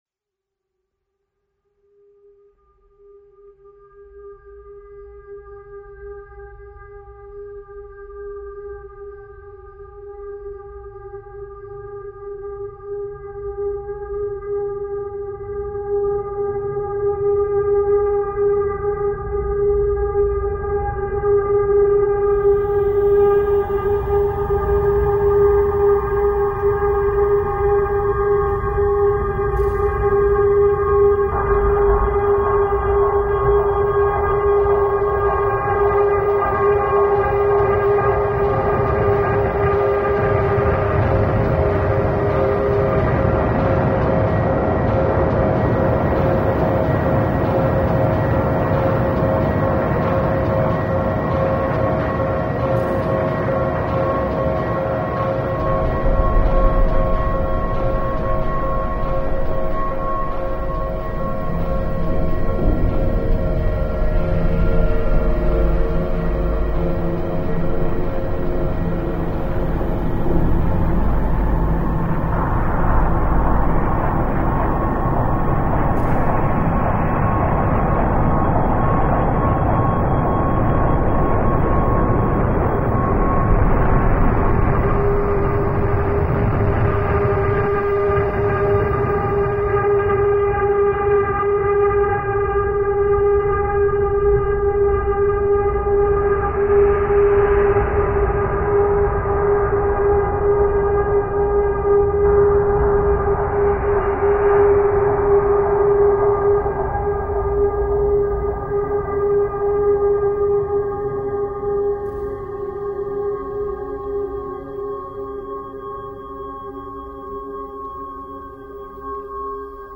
Haunting Ambo